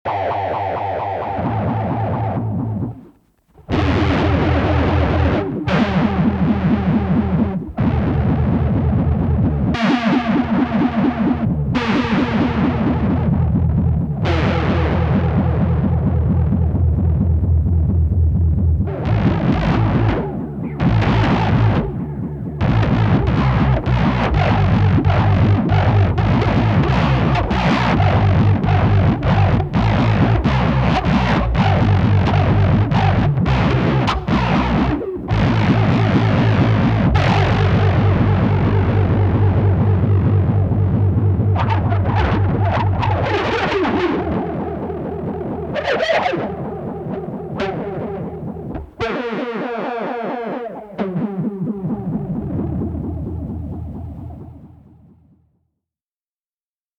Nicht unterhalten...nur zuhören Hab mal nur 3 Effekte verwendet...